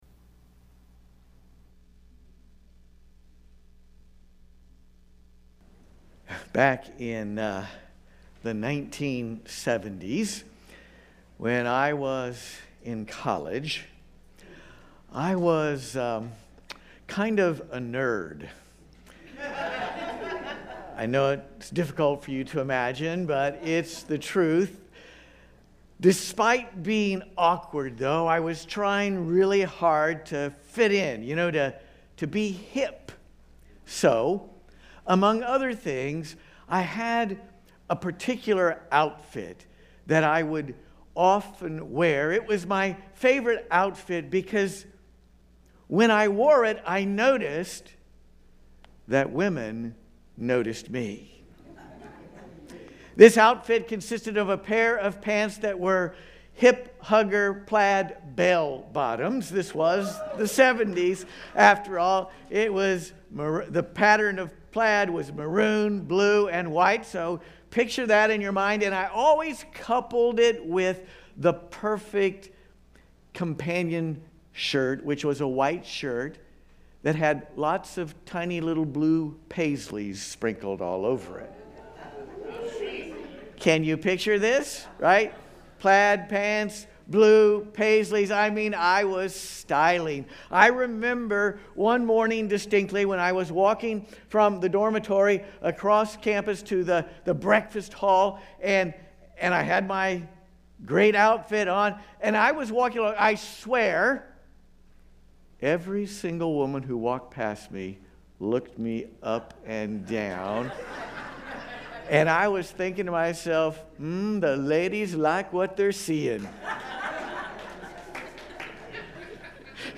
But it also identifies three values that are seldom discussed, yet critically important. This Sunday we'll explore those three critical values. LIVE Stream Replay